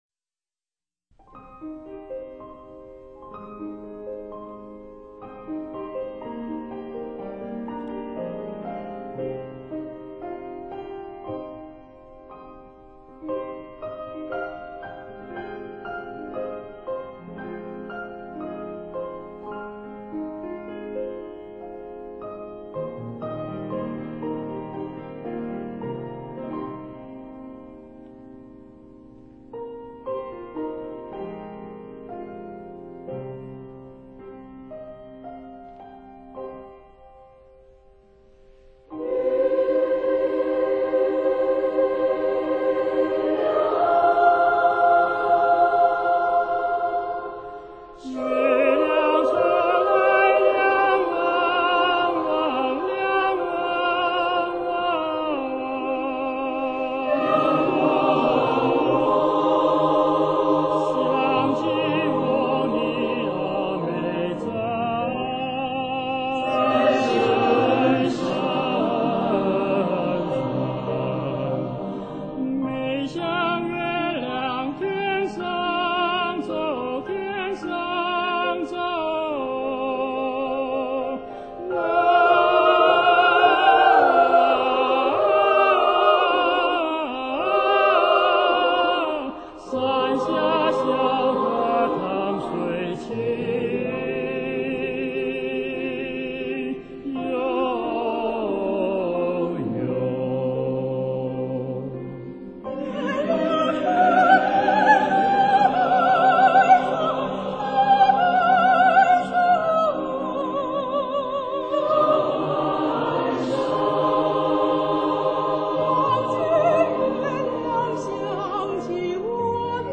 小 河 淌 水 引用: [light] 小 河 淌 水 [/light] [light] 百人合唱组合 [/light] 月亮出来亮汪汪， 想起我尼阿妹在深山， 妹像月亮天上走， 山下小河淌水清悠悠。